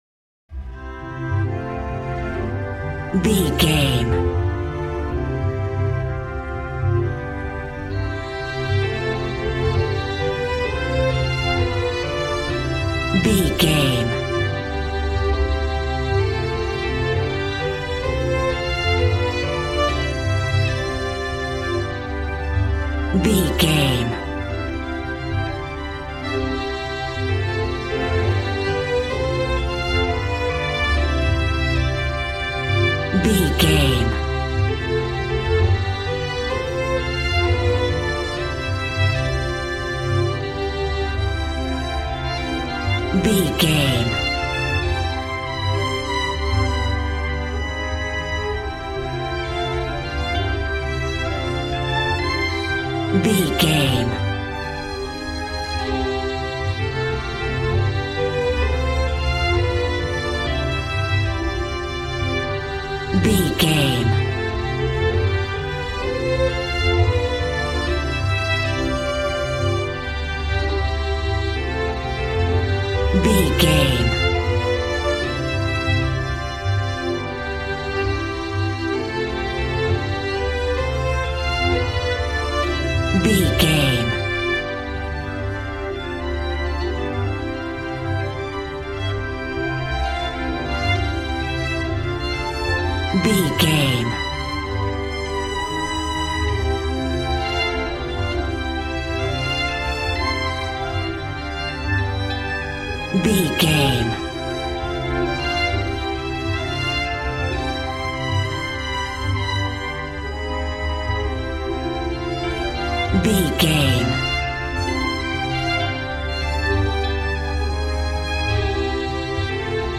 Ionian/Major
percussion
violin
cello